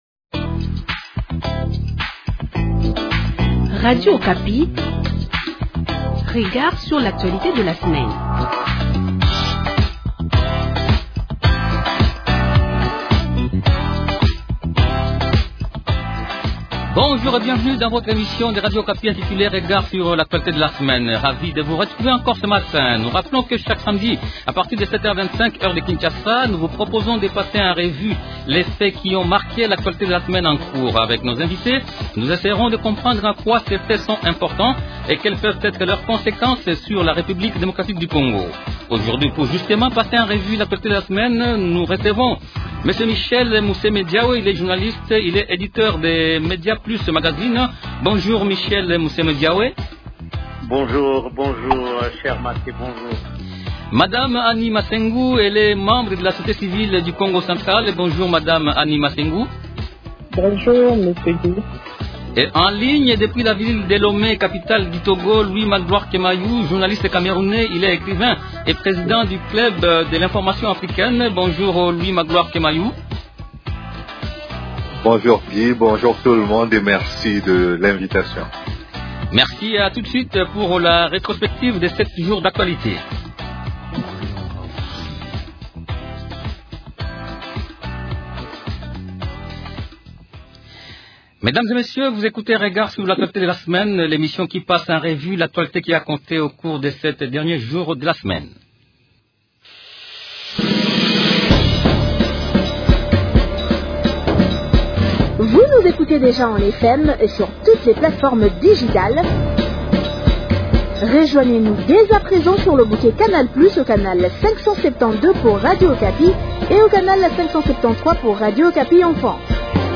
Journaliste.